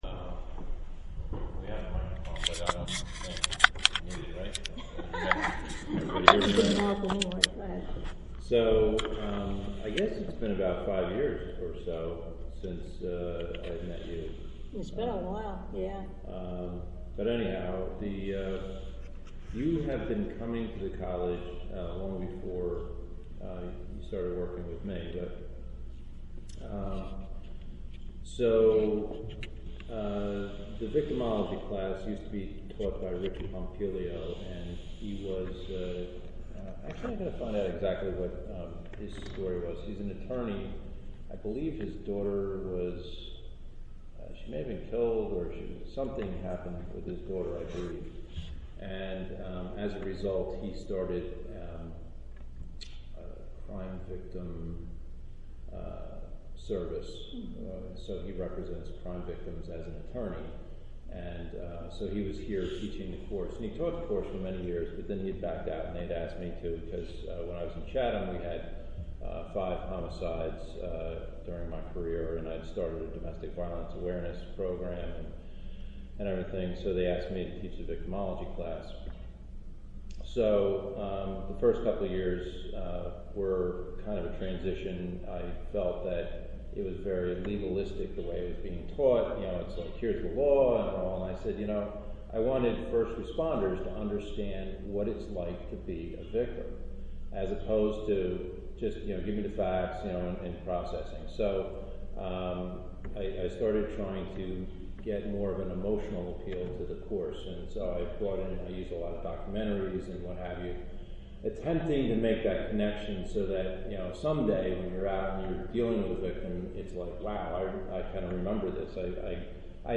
She included role playing skits and videos, speaking for about 90 minutes.